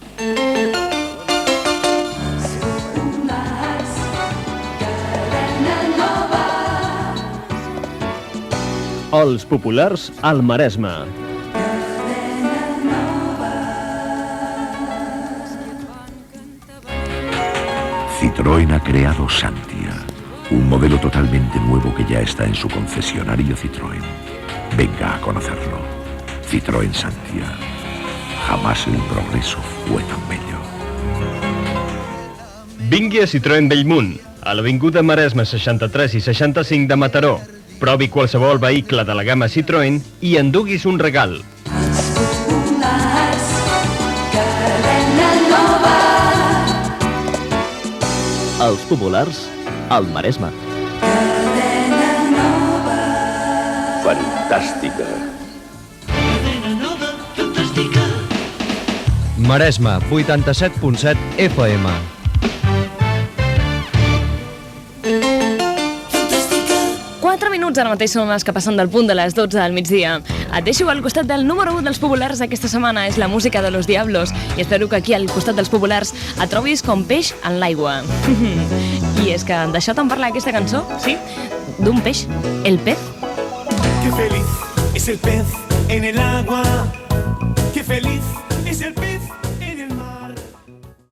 Identificació de "Els populars", publicitat, indicatiu de l'emissora, tema musical
Musical
FM